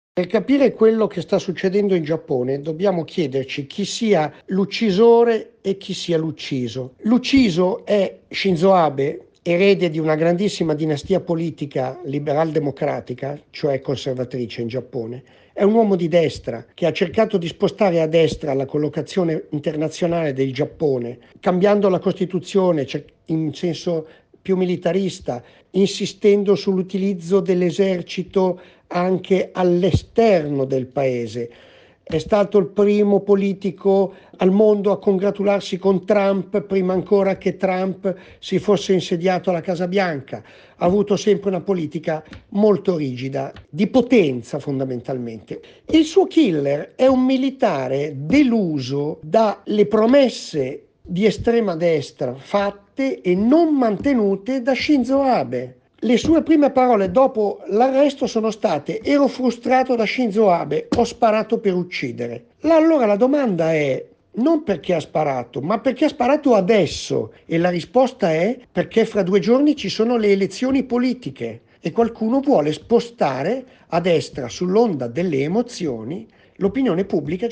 giornalista esperto di Giappone e Asia